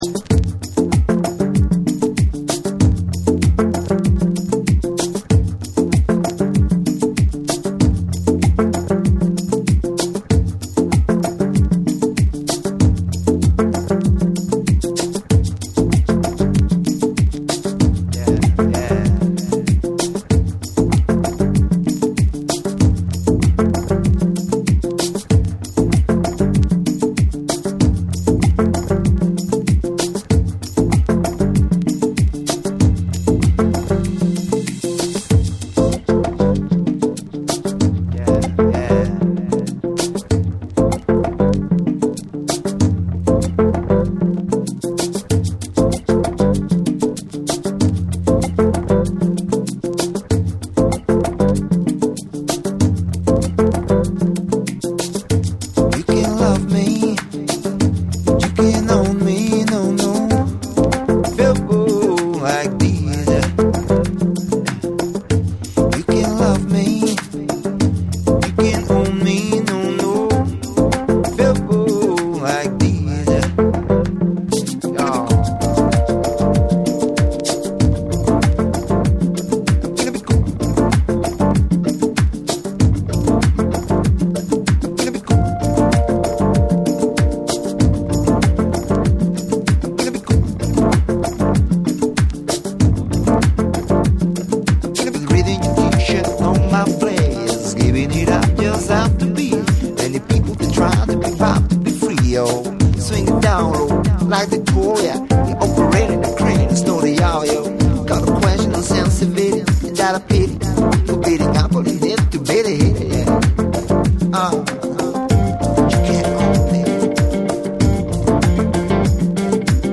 BREAKBEATS / ORGANIC GROOVE